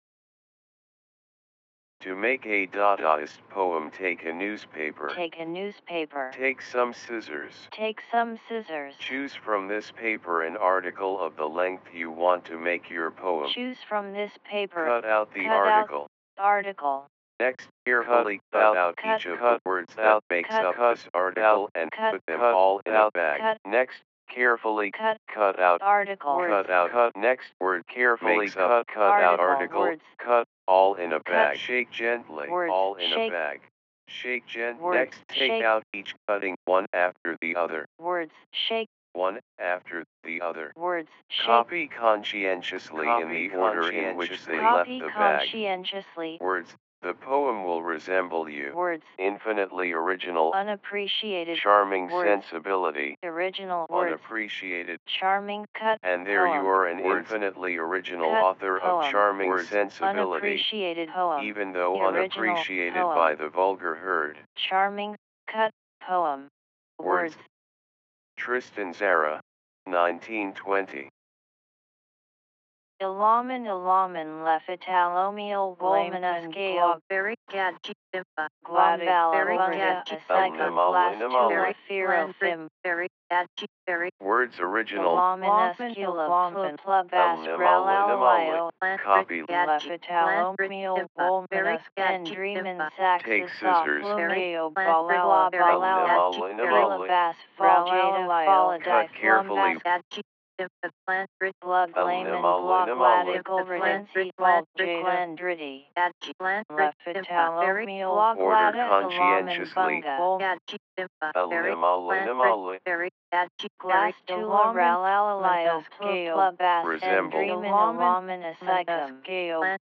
I recorded the instructions for creating a Dadaist poem by Tristan Tzara (1920) and got the on-board ‘read aloud voices’ to read the words, which came out quite robotic and without intonation. I decided to use a non human voice as I thought it would be interesting to see if I could create a piece that sounded like sound poetry.
I then applied Tzaras’ instructions to create the piece itself, using cut up, montage and rearrangement of the sound files.
I then applied the same process to two of Hugo Ball’s poems – ‘Gadji Beri Bimba’ and ‘Wolken’.